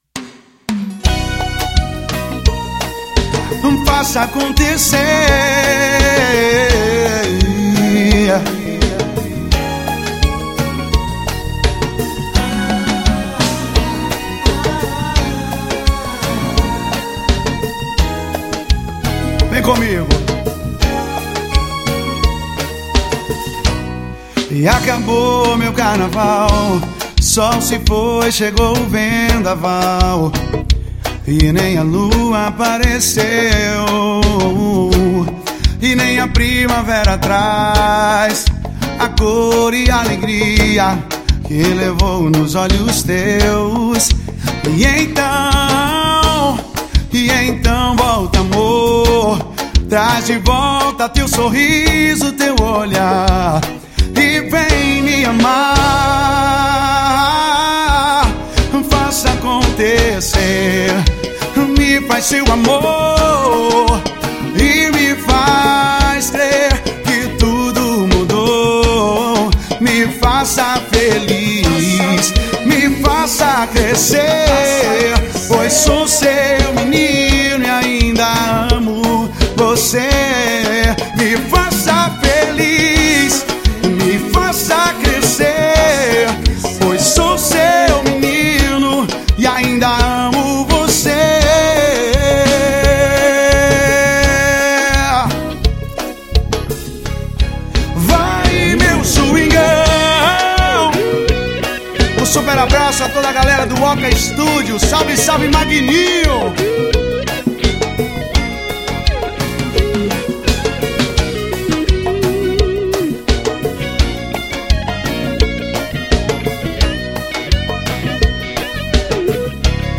EstiloSwingueira